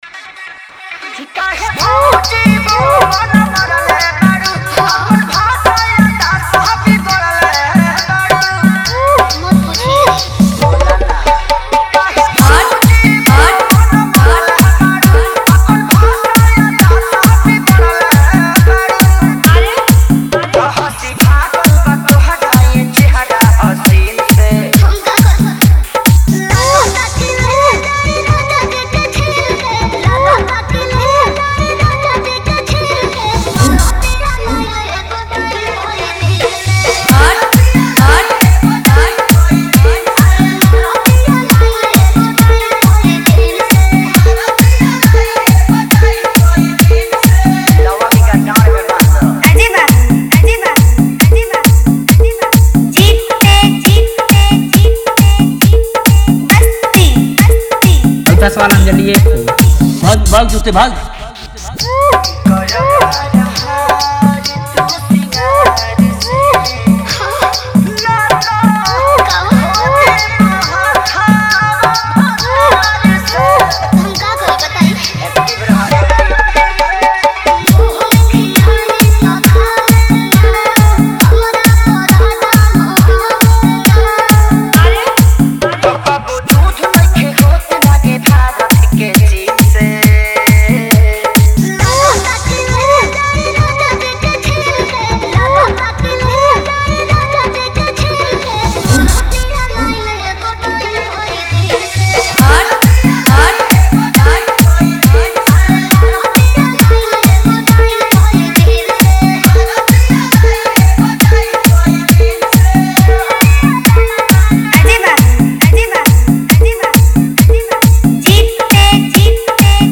Category : Bhojpuri Wala Dj Remix